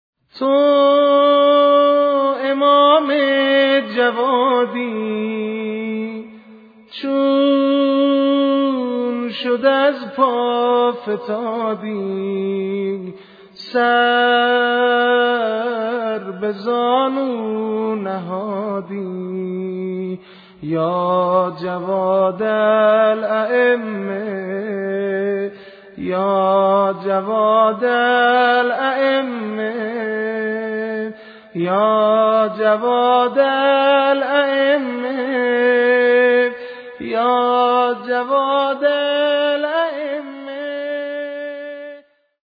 محمد الجواد شهادت نوحه تو امام جوادیغلامرضا سازگار